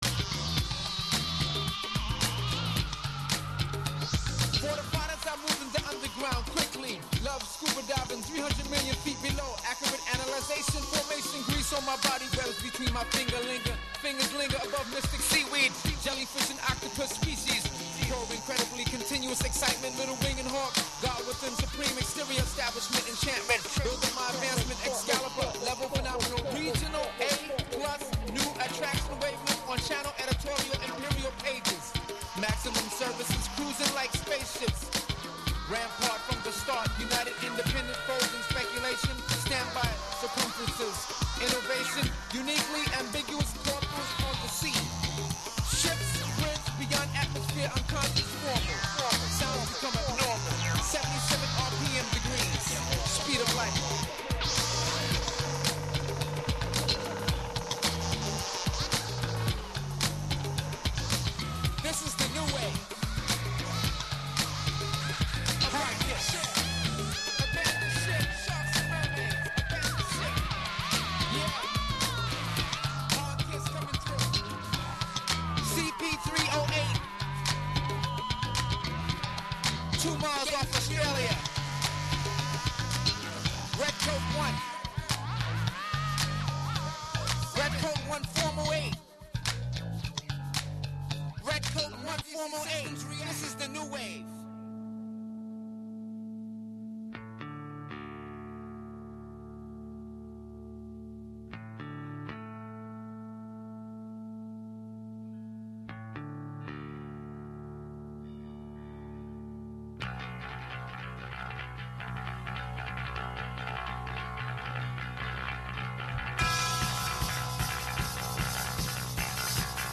enemy combatant radio for sunday 25 may 2003 ::: broadcasting from the san francisco indymedia center ... 3 hours in half-hour chunks